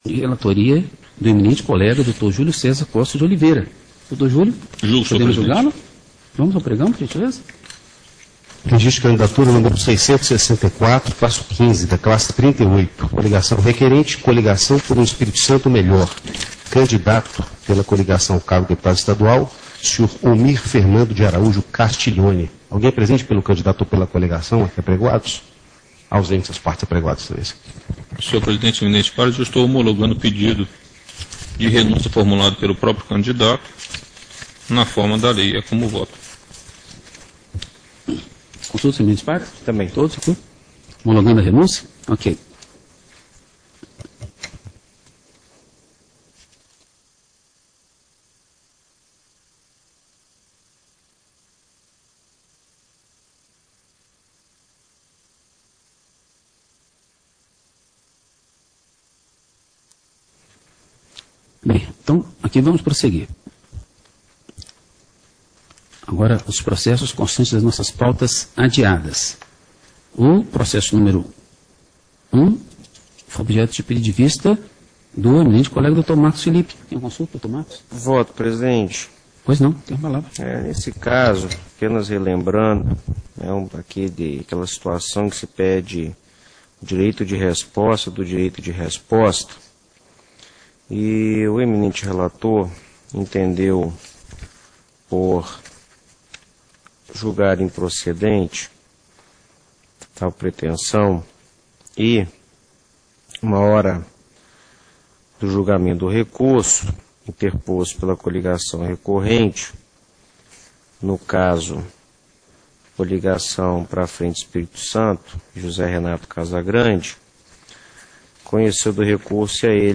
TRE-ES sessão do dia 23 09 14